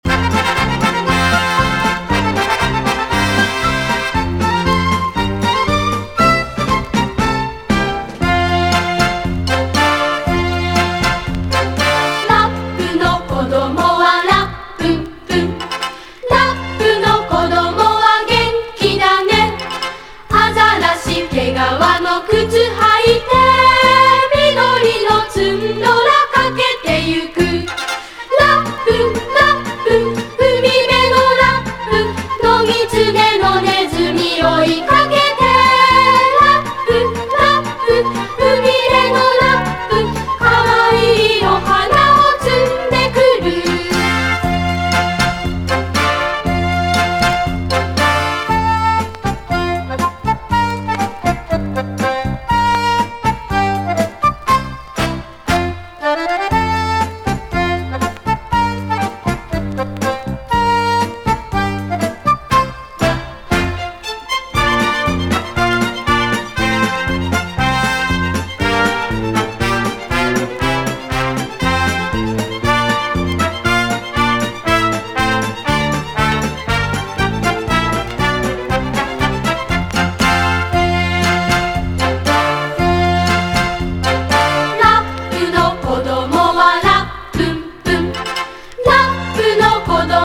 JAPANESE GROOVE / DRUM / DRUM BREAK / JAPANESE JAZZ
衝撃のドラムが炸裂する
ドープ且つクールな